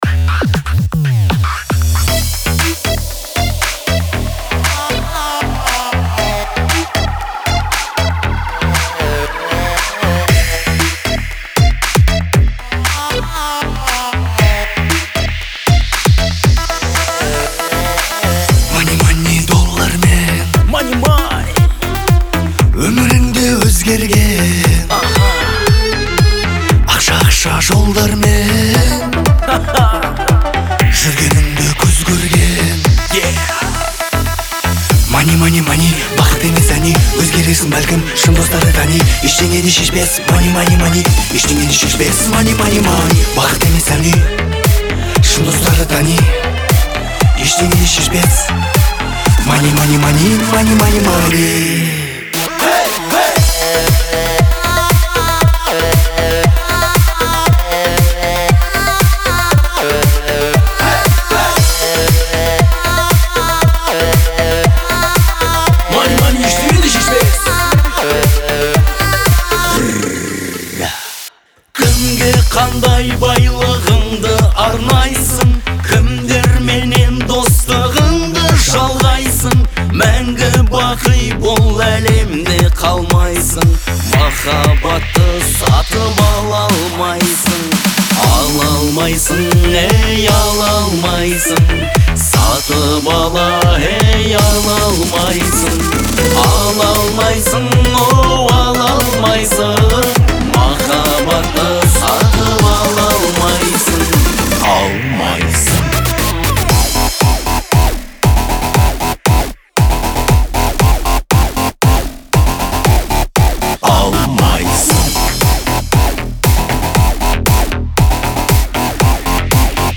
яркая и энергичная композиция
выполненная в жанре поп-рок.